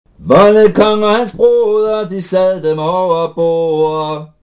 Balladens grundform: To linjer, Etteren og Toeren.